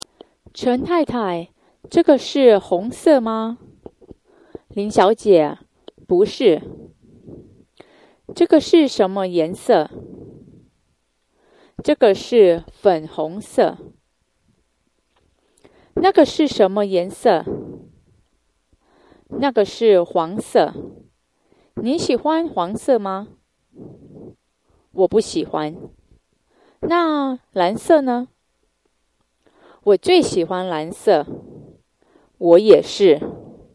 Lesson5-conversation.mp3